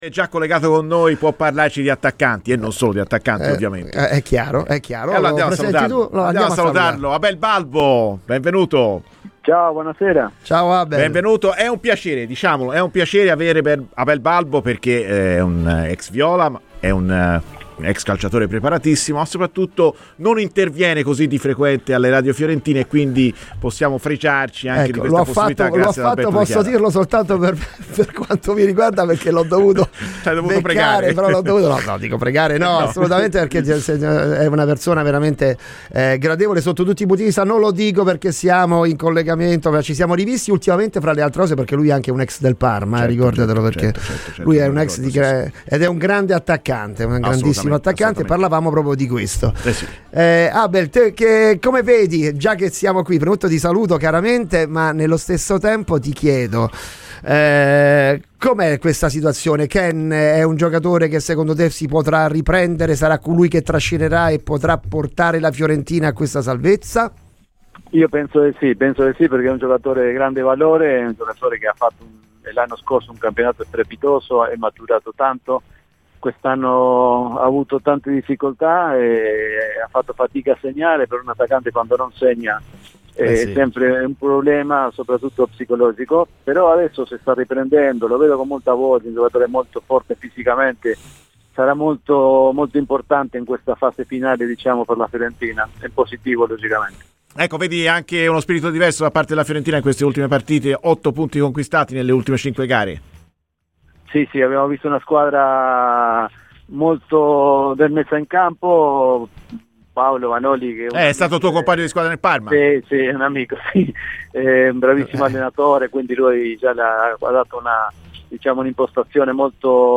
Per il resto dell’intervista, ascolta il podcast di Radio FirenzeViola!